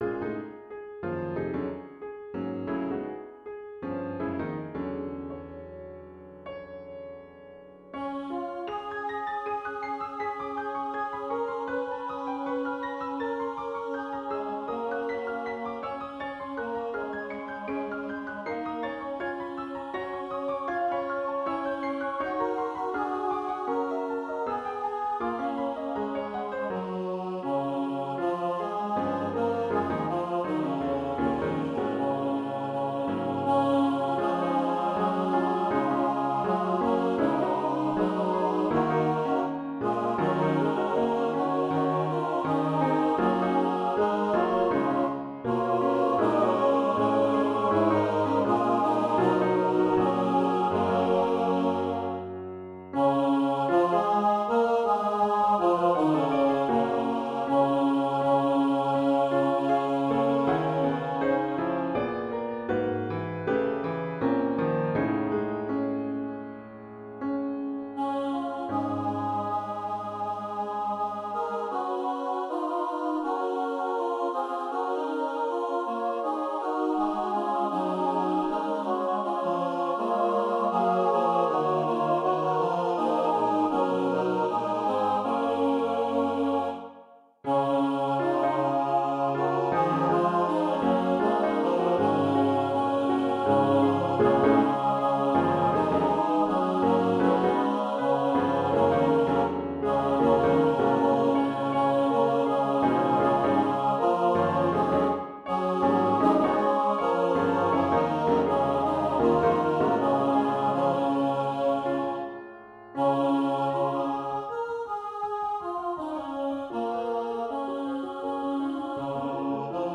Sometimes, you (and, by you, I mean I) want to color in an otherwise nice spiritual arrangement with every crayon in the chromatic box.
Except for the recording, which is why there is a computer-realized placeholder until a) I get a good recording, or b) I tweak the realization so it’s less clunky.